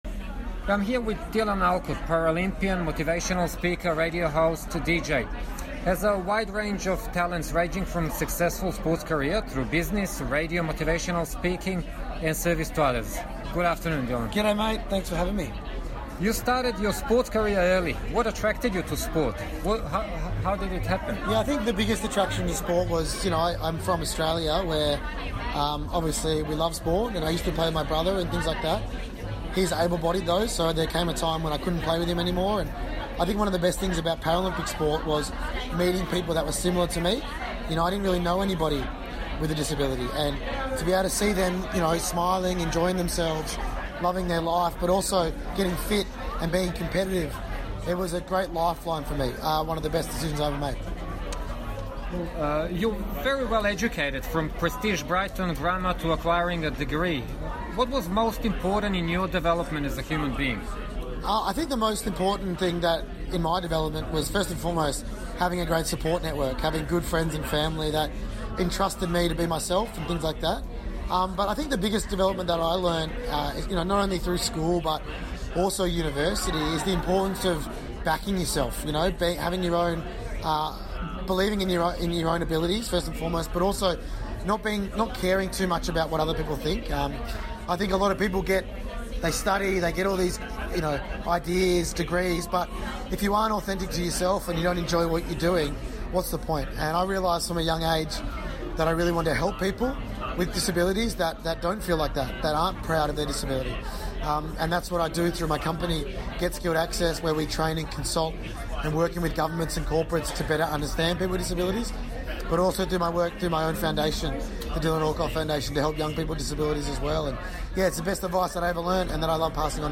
Australian Paralympian, motivational speaker and radio host Dylan Alcott talks to SBS Serbian about issues that people with disability face in everyday life.
dylan_alcot_intervju_original_.mp3